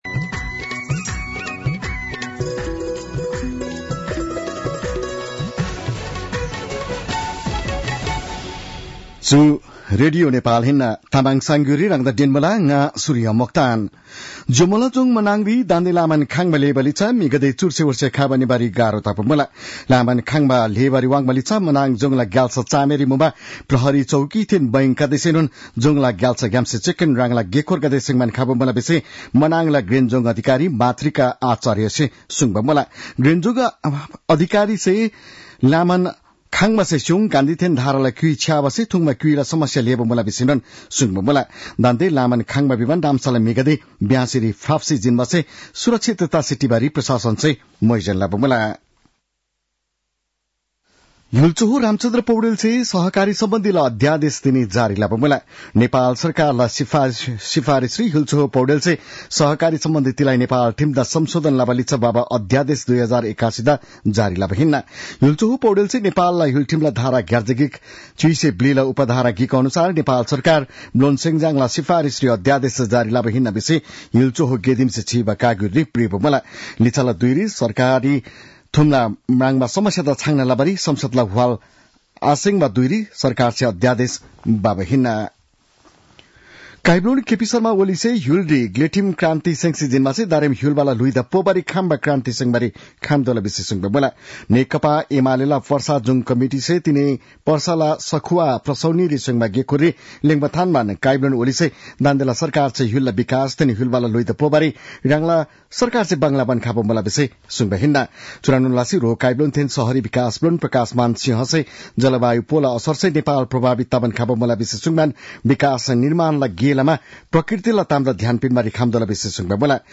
तामाङ भाषाको समाचार : १५ पुष , २०८१